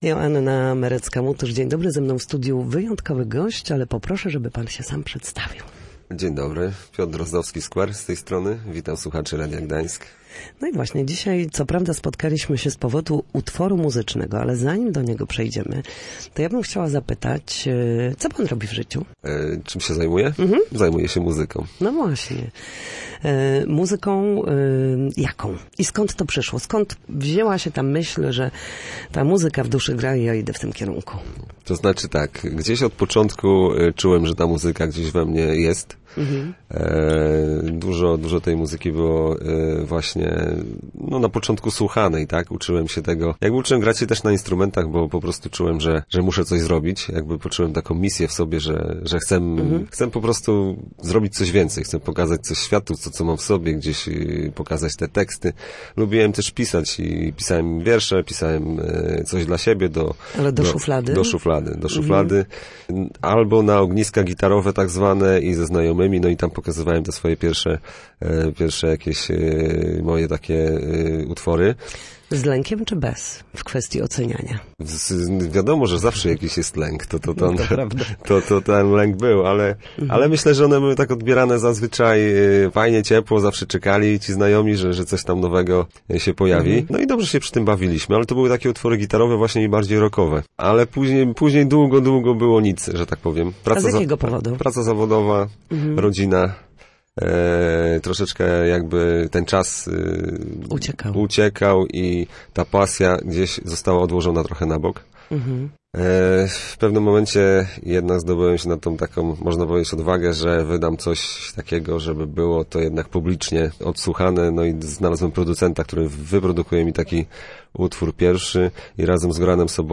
Na naszej antenie mówił o nowym projekcie muzycznym.